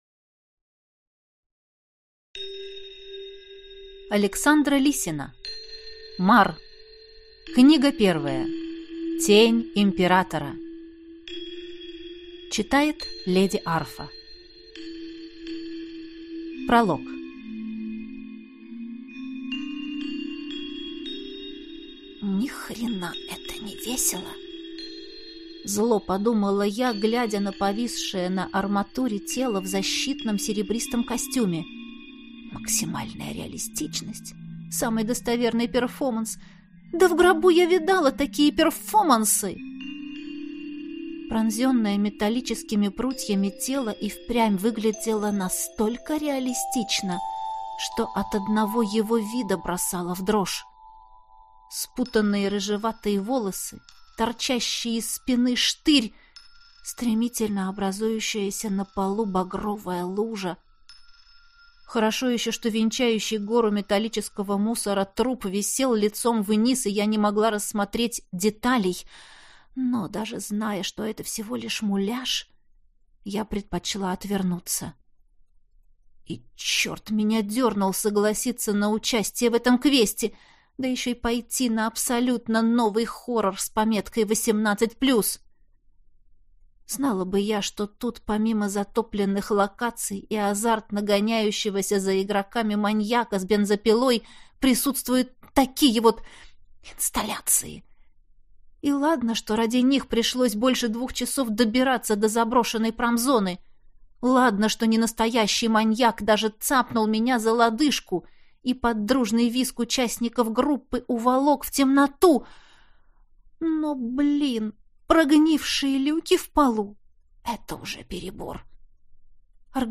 Прослушать фрагмент аудиокниги Мар. Тень императора Александра Лисина Произведений: 42 Скачать бесплатно книгу Скачать в MP3 Вы скачиваете фрагмент книги, предоставленный издательством